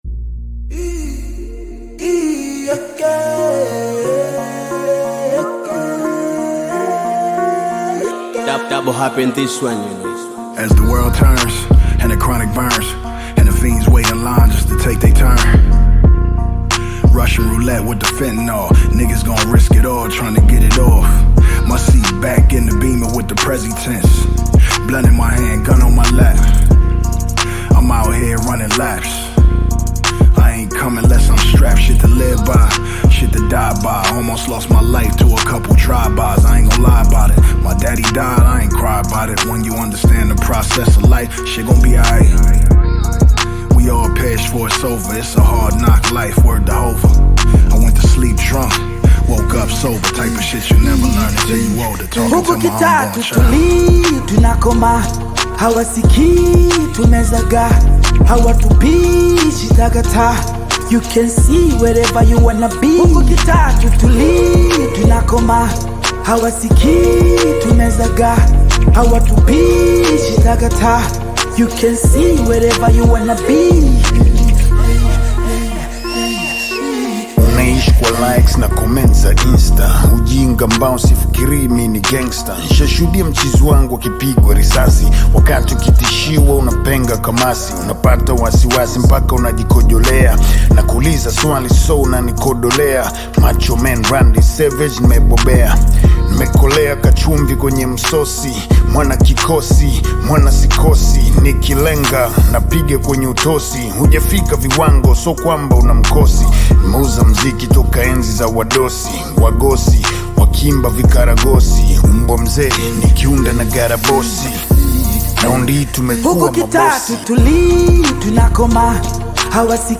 Bongo Flava music track
Tanzanian Bongo Flava artist and rapper